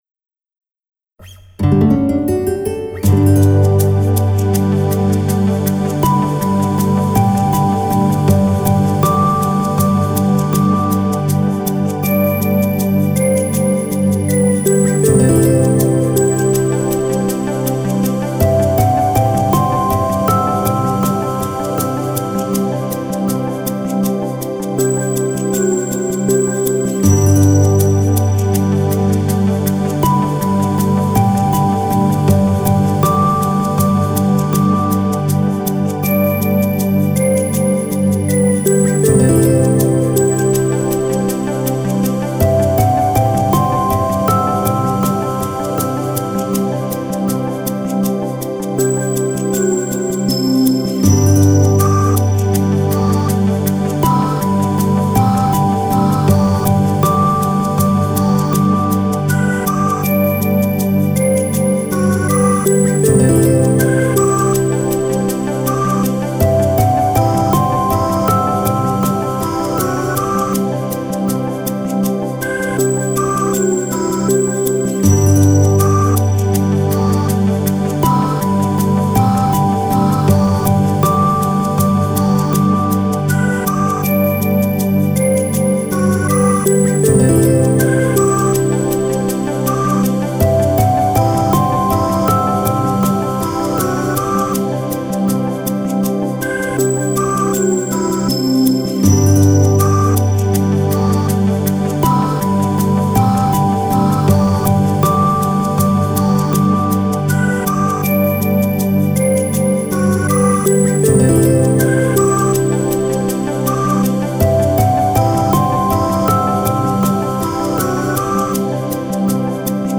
幻想的なシンセパッドサウンドと、アコースティック・ギター、神秘的なシンセサウンドが特徴の楽曲です。